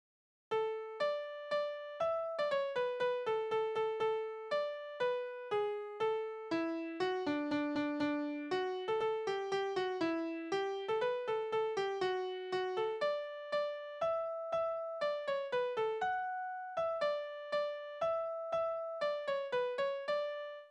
Balladen: Edelmann und Höriger
Tonart: D-Dur
Taktart: 2/4
Tonumfang: große None
Besetzung: vokal